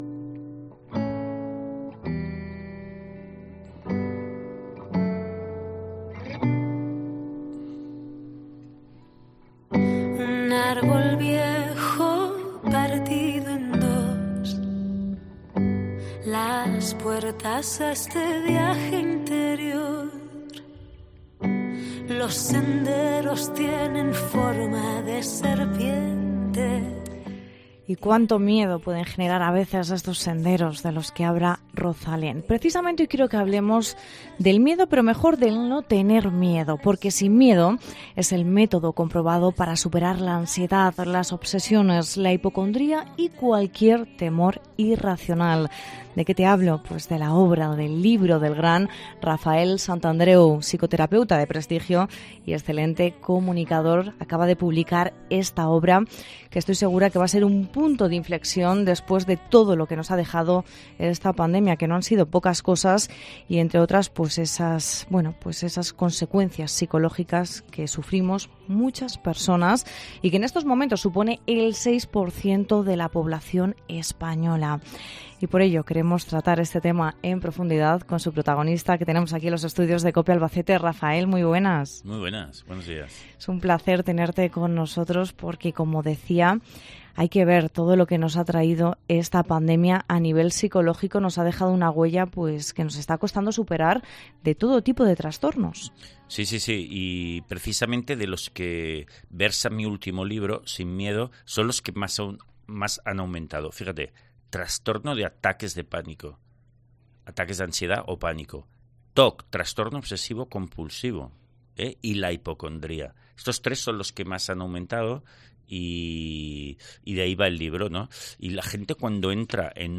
Entrevista con Rafael Santandreu